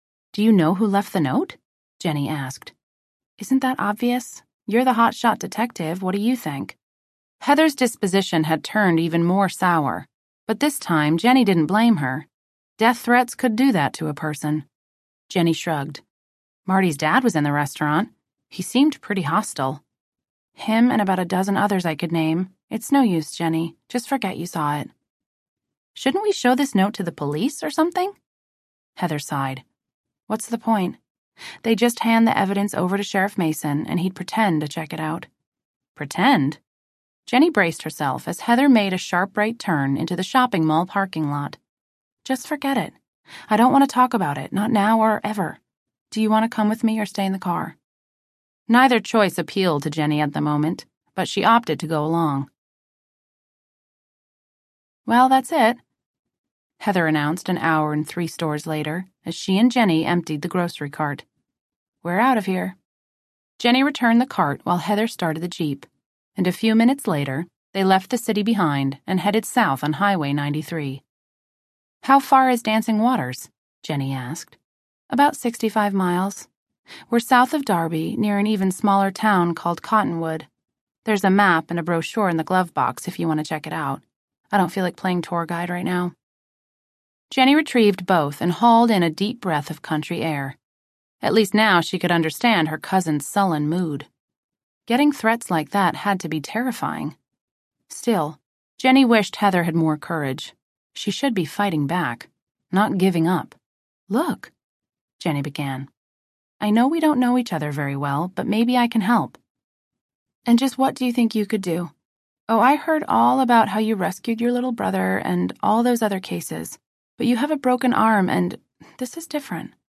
Betrayed (The Jennie McGrady Mysteries, Book #7) Audiobook
5.2 Hrs. – Unabridged